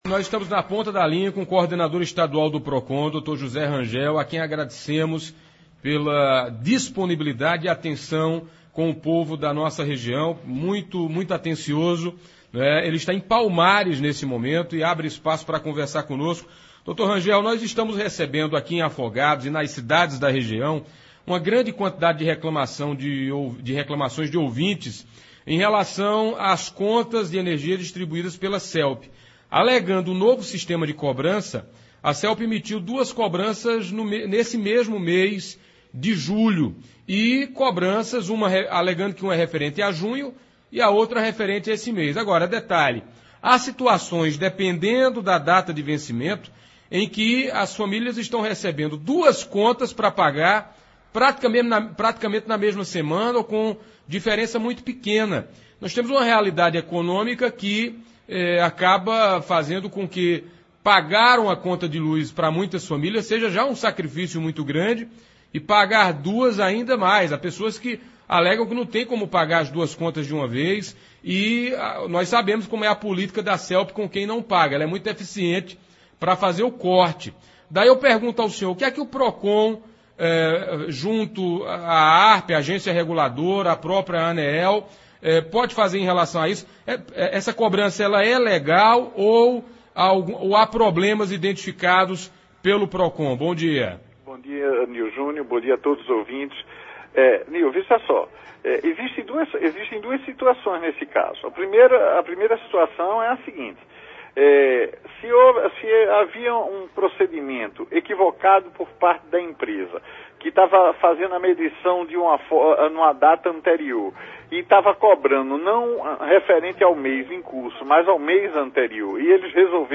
Diante do grande volume de reclamações da população em relação à cobrança da Celpe que devido a uma mudança na forma de cobrança a Pajeú ouviu está manhã (23), o coordenador estadual do Procon, Dr. José Rangel, para saber o que o órgão poderia fazer pelos consumidores da região.